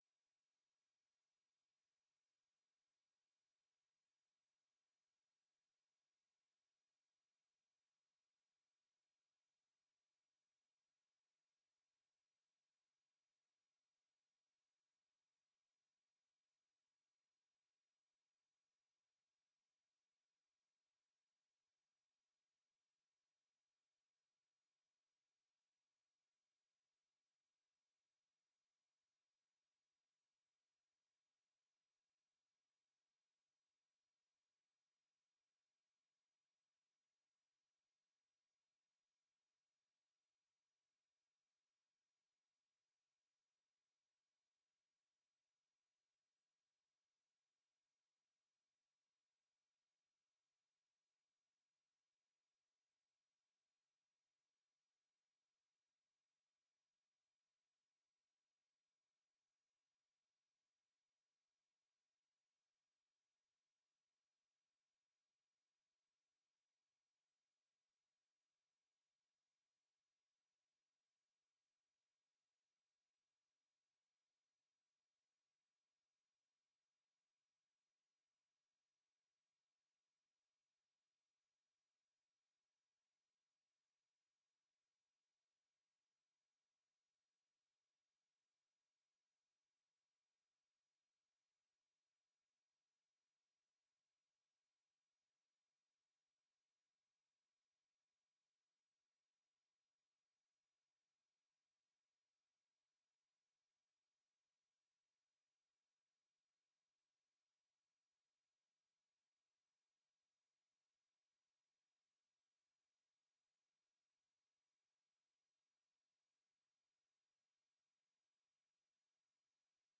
beeldvormende raad 12 juni 2025 19:30:00, Gemeente Doetinchem